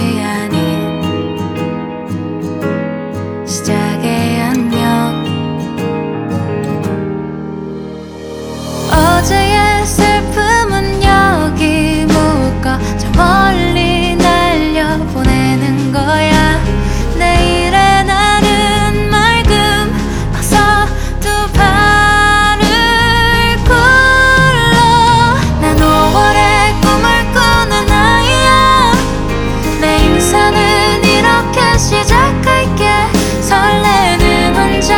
Жанр: Поп музыка / Соундтрэки
K-Pop